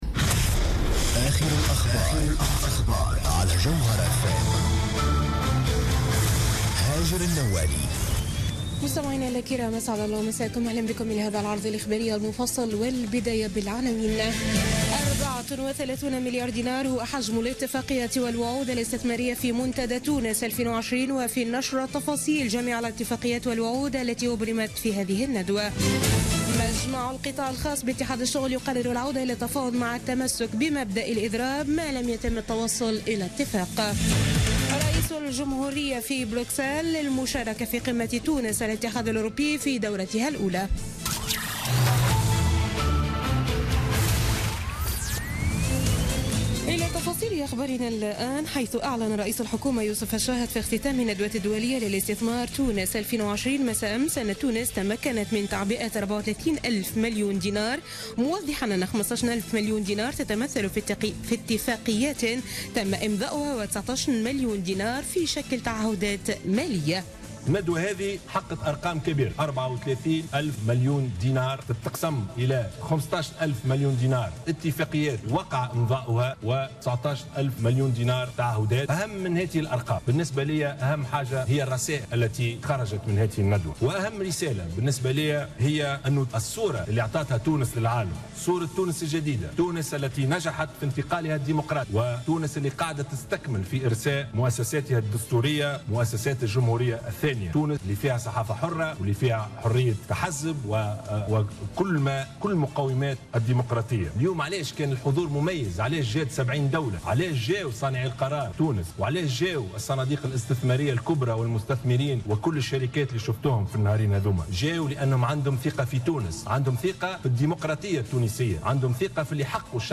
نشرة أخبار منتصف الليل ليوم الخميس 1 ديسمبر 2016